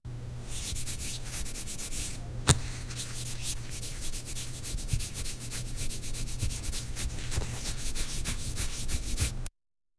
Wiping face with napkin
wiping-face-with-napkin-fvwnyhdo.wav